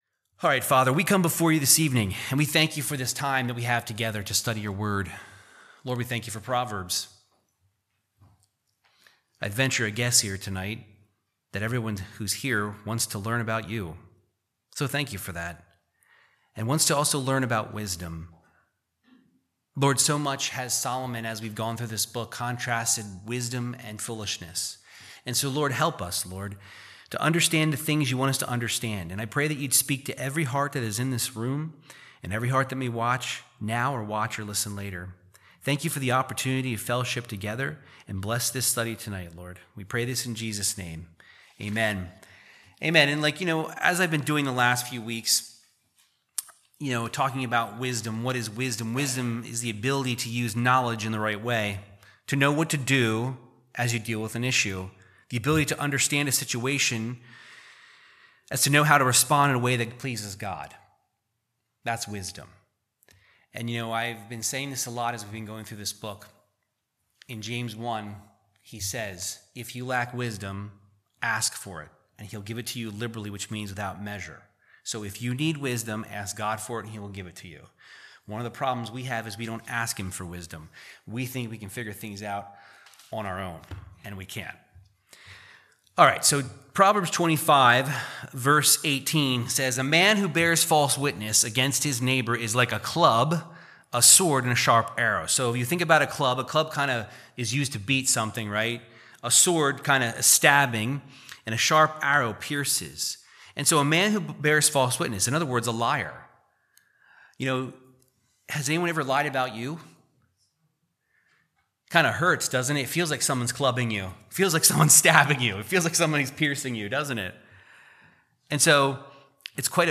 Verse by verse Bible Teaching of the wisdom of King Solomon in Proverbs 25:18 - 26:28